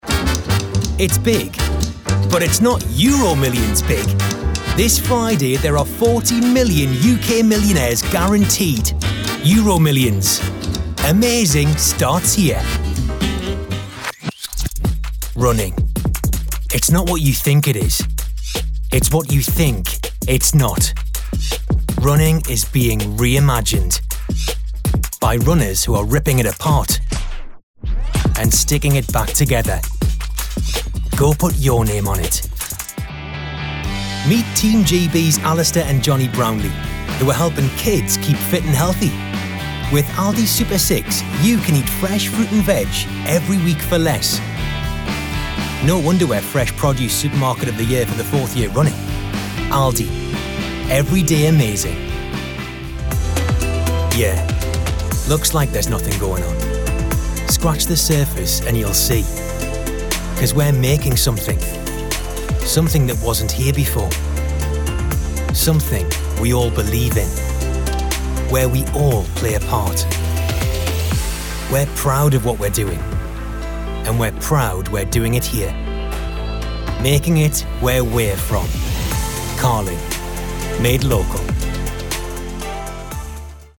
North-East, Warm, Approachable, Engaging, Relatable
Andrew_Hayden-Smith_-_Voice_Reel.mp3